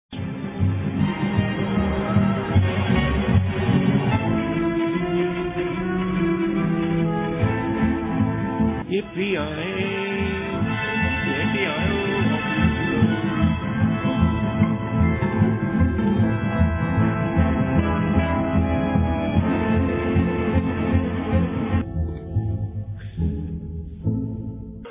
A collage of various sound clips from the Signature Tune